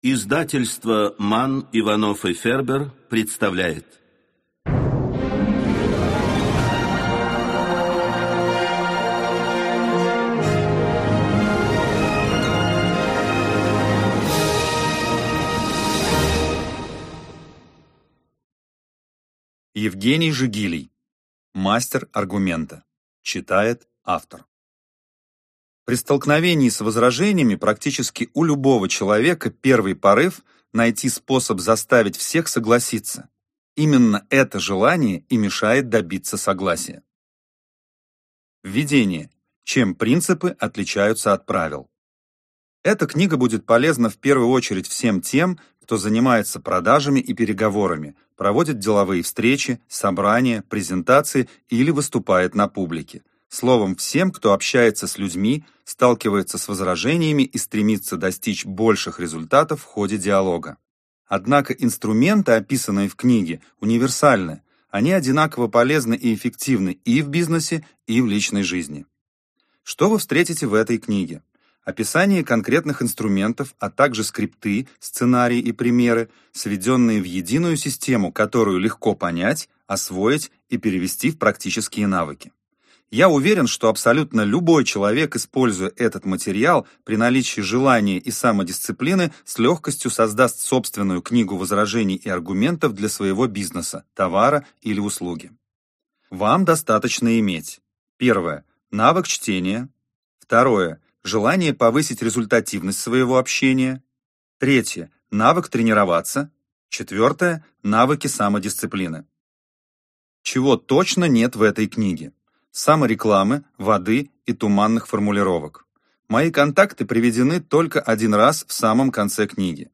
Аудиокнига Мастер аргумента | Библиотека аудиокниг